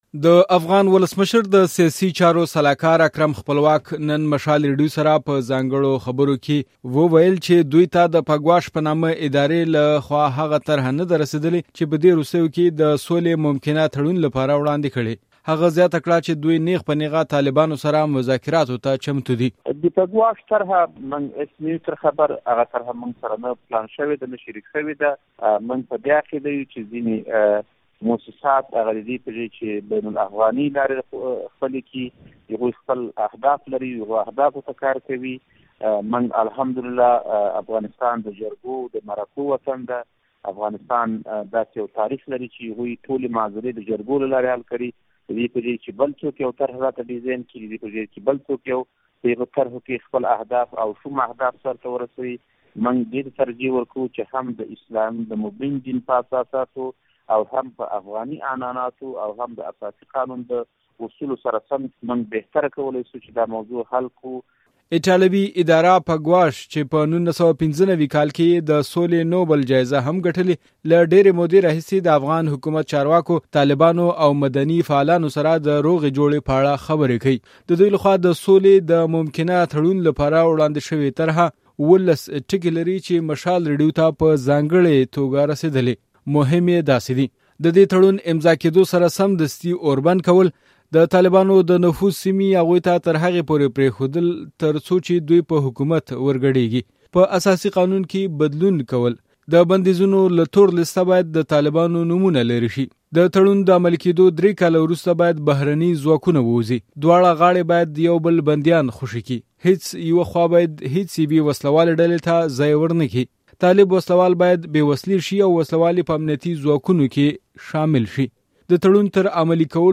ځانګړی راپور: حکومت سولې لپاره د پګواش طرحه رد او طالبانو تاييد کړه